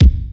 • Rap Bass Drum Sample C Key 106.wav
Royality free bass drum one shot tuned to the C note. Loudest frequency: 238Hz
rap-bass-drum-sample-c-key-106-cIP.wav